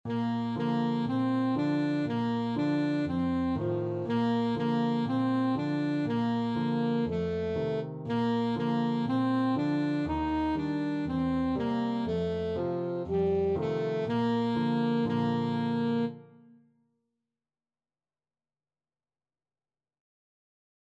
Alto Saxophone
4/4 (View more 4/4 Music)